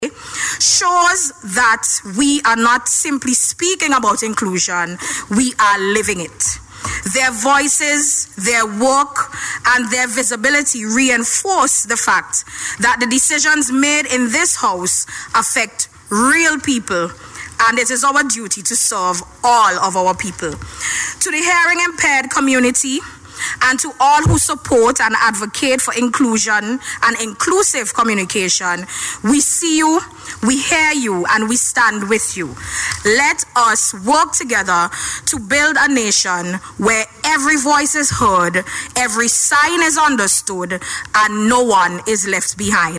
Speaking during the last sitting of Parliament, Minister Peters said the inclusion of persons with disabilities, including the integration of sign language, highlights the Government’s dedication to serving all citizens.